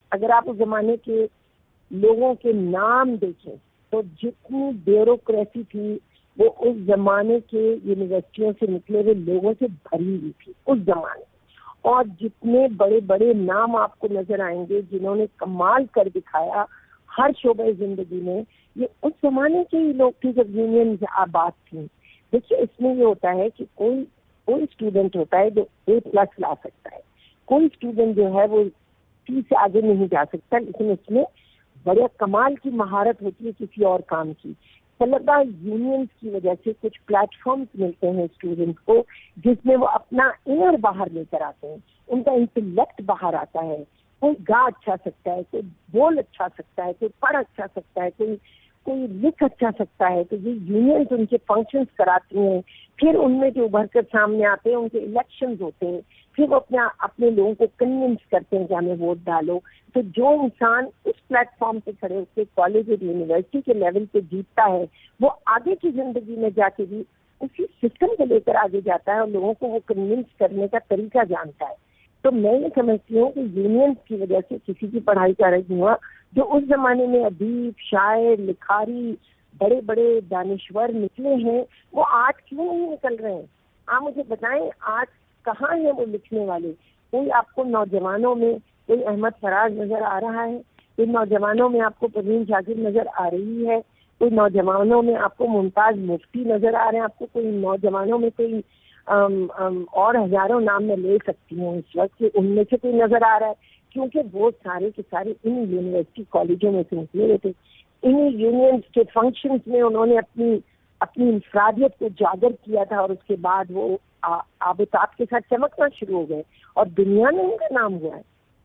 اس سلسلے میں ایم کو ایم کی سینیٹر اور سابق طالب علم رہنما خوش بخت شجاعت سے خصوصی گفتگو پیش کی جارہی ہے۔